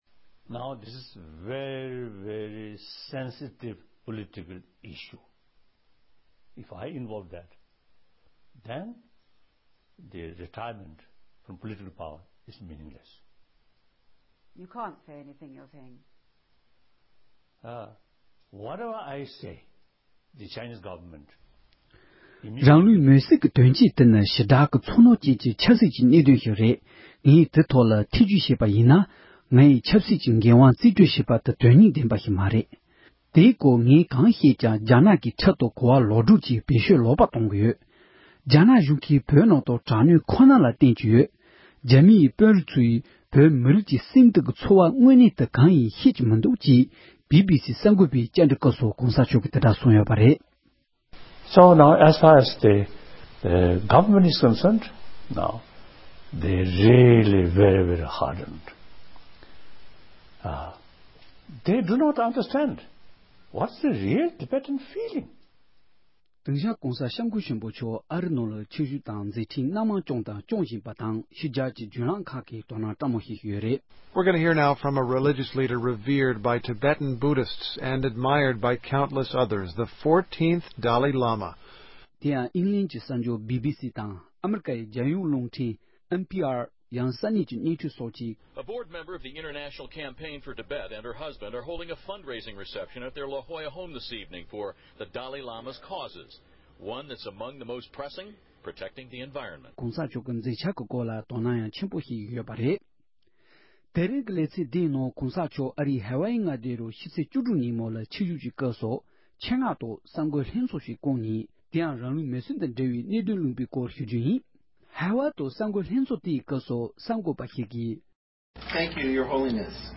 སྒྲ་ལྡན་གསར་འགྱུར། སྒྲ་ཕབ་ལེན།
གནས་ཚུལ་ཕྱོགས་སྒྲིག་དང་སྙན་སྒྲོན་ཞུས་པར་གསན་རོགས་གནོངས༎